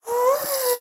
moan4.wav